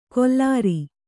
♪ kollāri